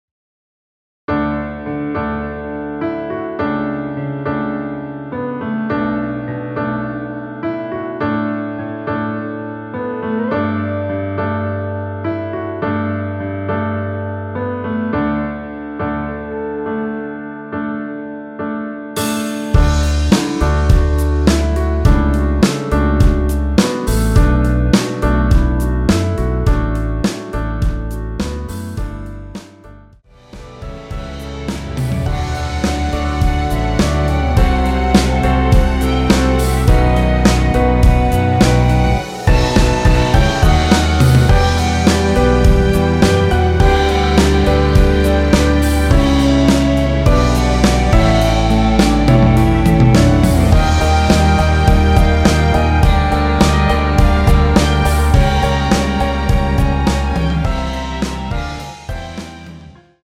원키에서(-6)내린 멜로디 포함된 MR입니다.(미리듣기 확인)
앞부분30초, 뒷부분30초씩 편집해서 올려 드리고 있습니다.
중간에 음이 끈어지고 다시 나오는 이유는